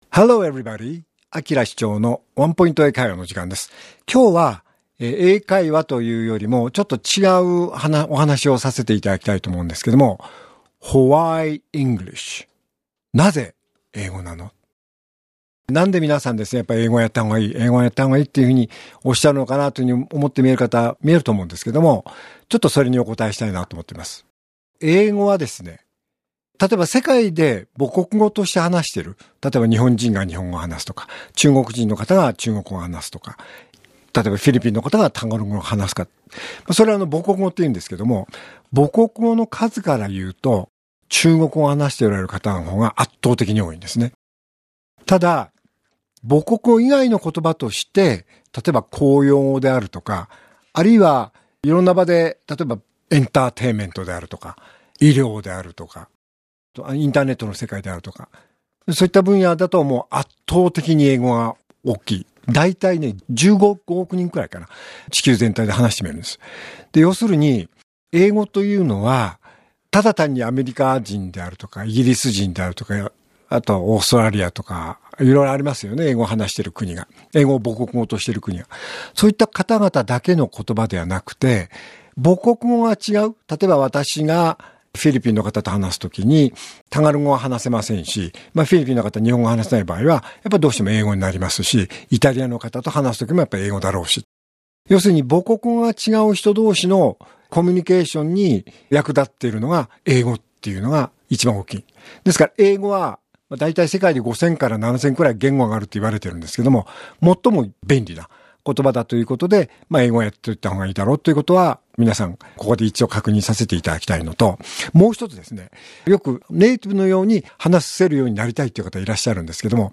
R5.7 AKILA市長のワンポイント英会話